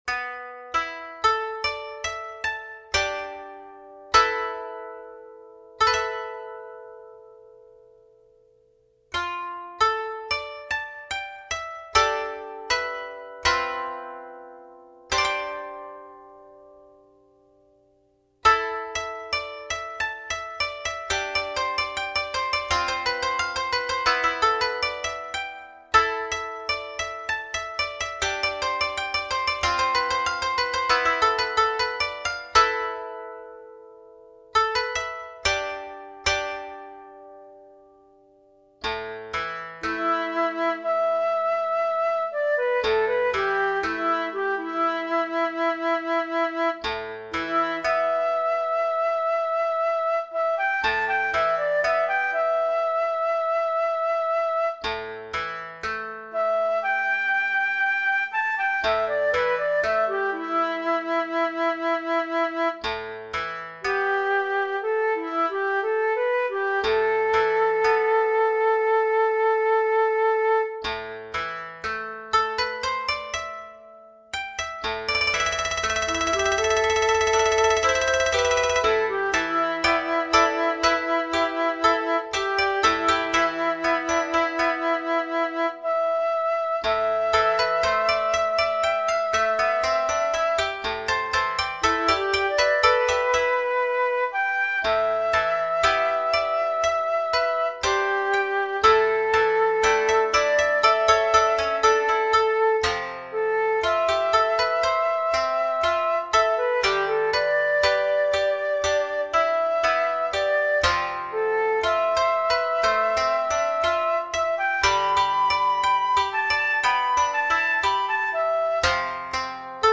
【編成】箏２・十七絃・尺八（一尺六寸） 雪が舞う情景の印象を綴った曲です。